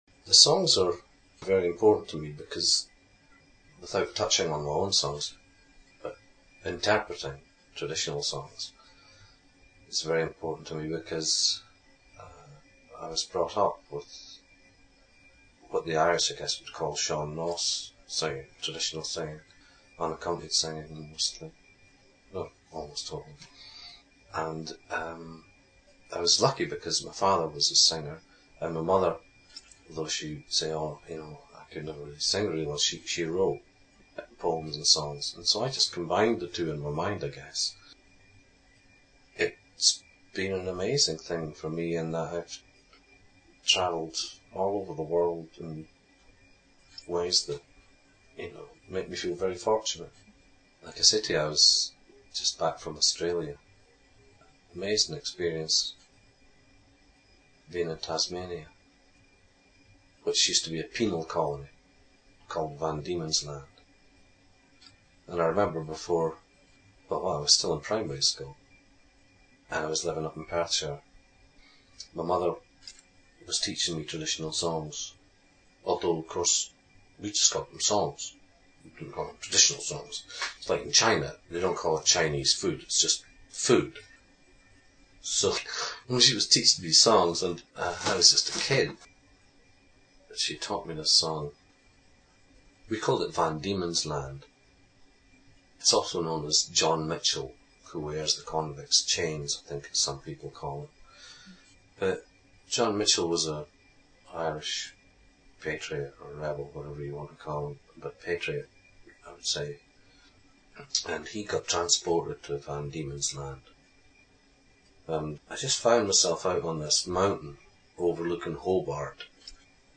Here’s an audio clip of Andy M discussing how his career began in traditional music, and it coming full circle when touring Australia.